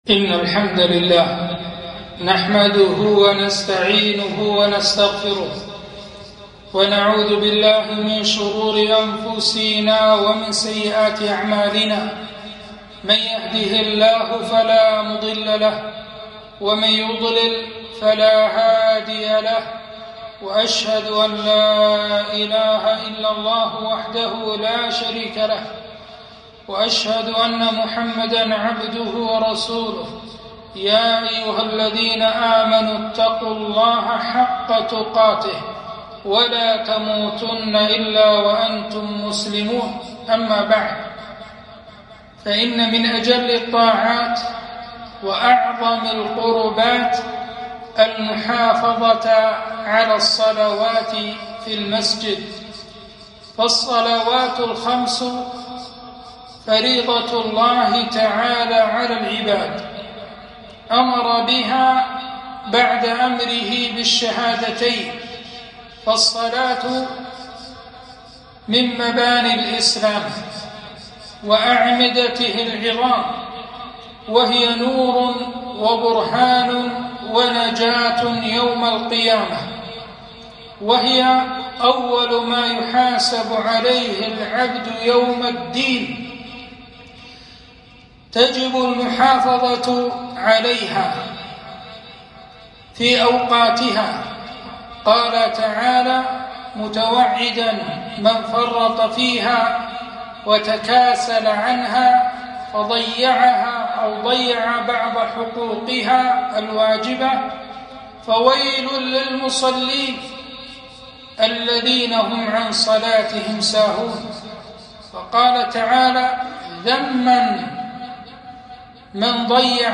خطبة - الصلاة خير من النوم